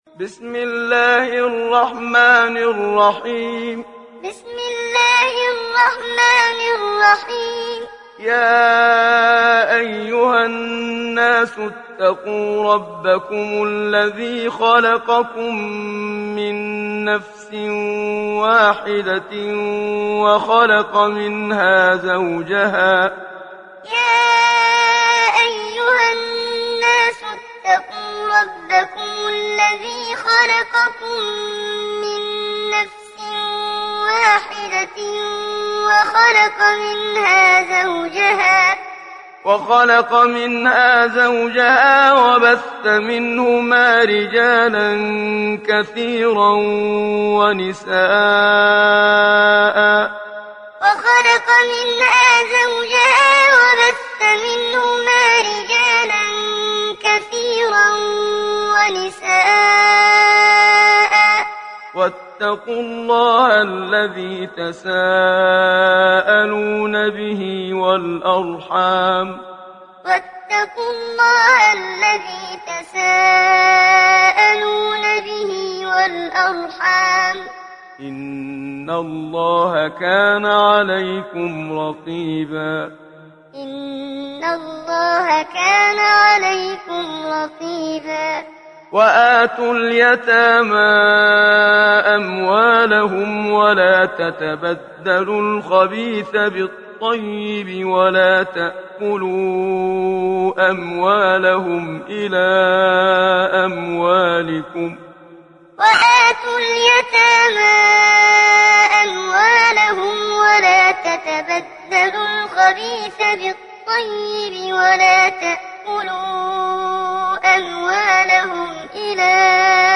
Surat Annisa Download mp3 Muhammad Siddiq Minshawi Muallim Riwayat Hafs dari Asim, Download Quran dan mendengarkan mp3 tautan langsung penuh
Download Surat Annisa Muhammad Siddiq Minshawi Muallim